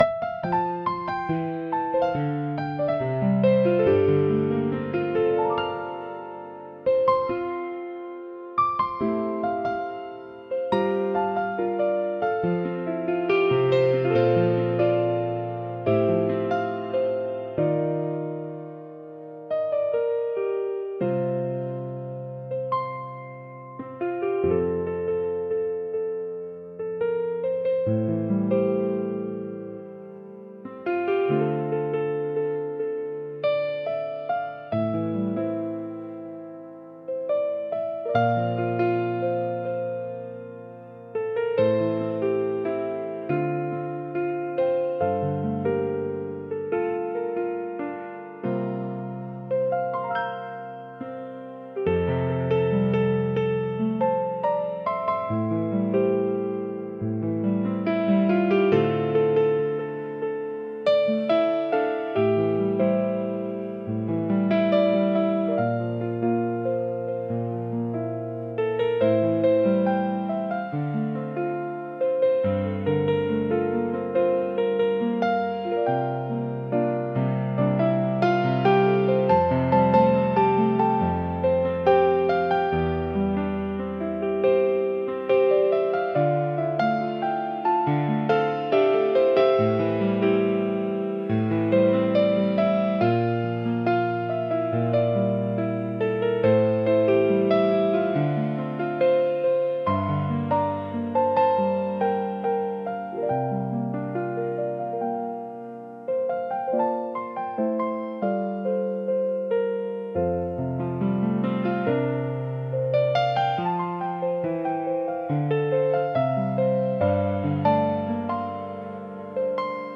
繊細で情感豊かな空気を醸し出すジャンルです。